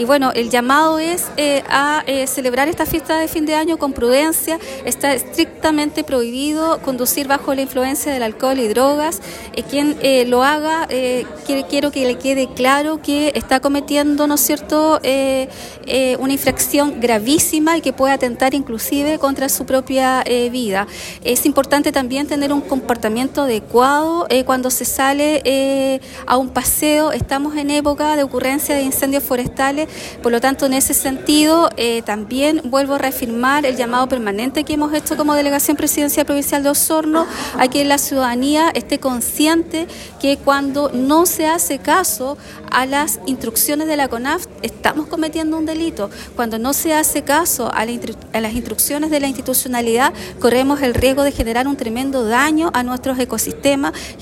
La Delegada también hizo un llamado a la responsabilidad de la comunidad durante estos días festivos, instando a las personas a ser conscientes de la importancia de actuar con prudencia y respeto hacia los demás. Uno de los puntos que enfatizó fue la importancia de evitar conducir bajo los efectos del alcohol, señalando que durante las fiestas, los accidentes de tráfico suelen aumentar debido a la ingesta de bebidas alcohólicas.